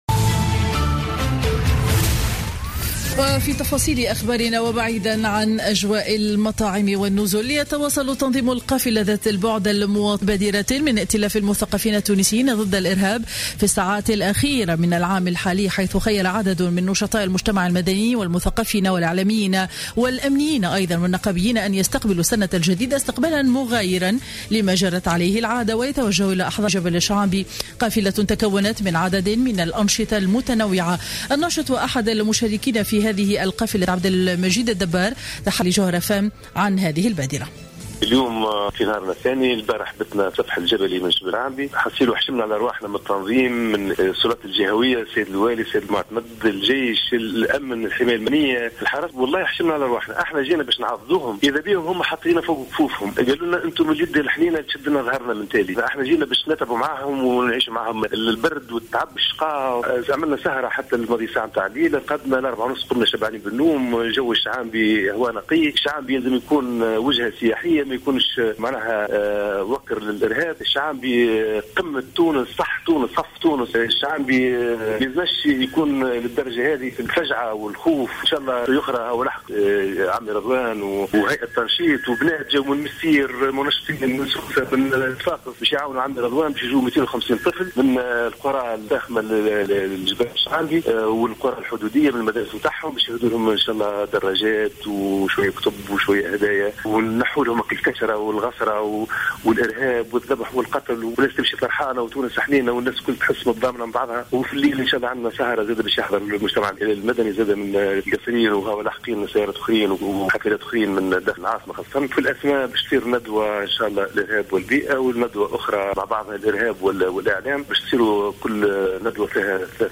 نشرة أخبار منتصف النهار ليوم الخميس 31 ديسمبر 2015